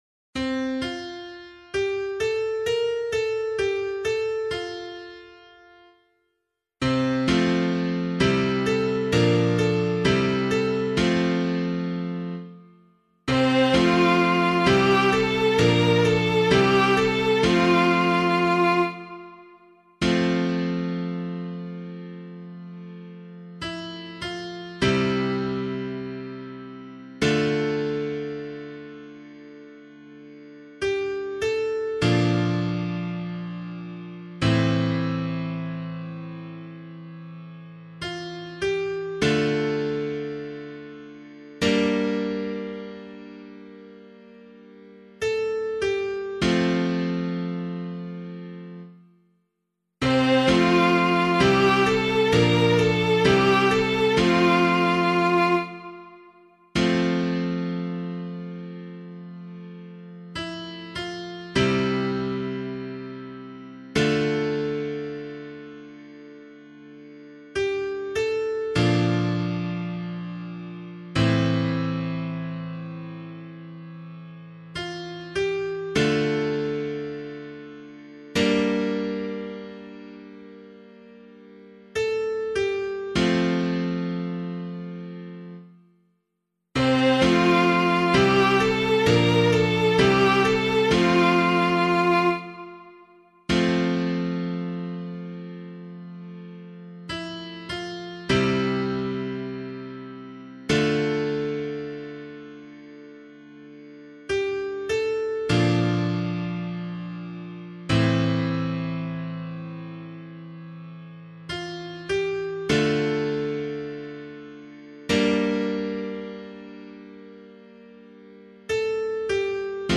280 Peter and Paul Day Psalm [LiturgyShare 6 - Oz] - piano.mp3